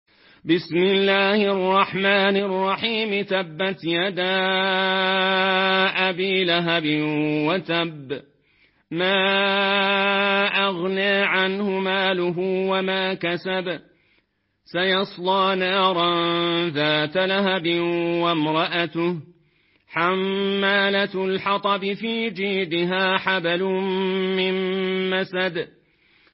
مرتل ورش عن نافع